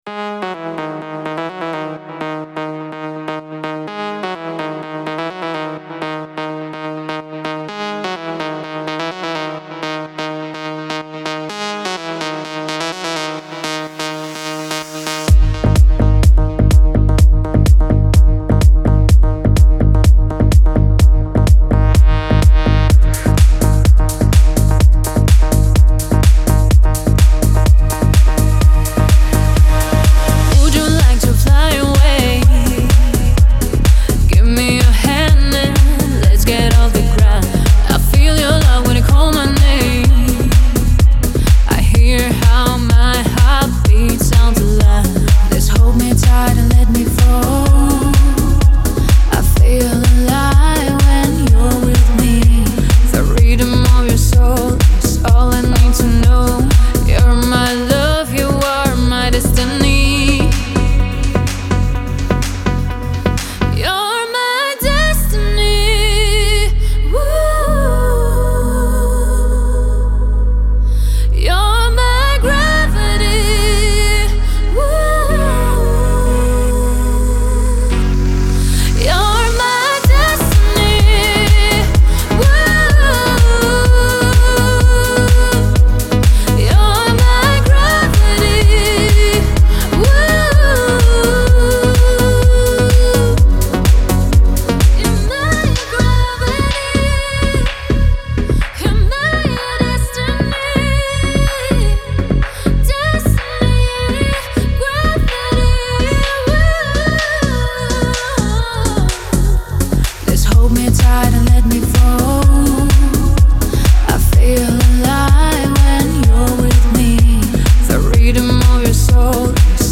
pop , dance , эстрада , диско